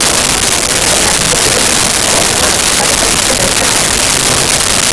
Bar Crowd 01
Bar Crowd 01.wav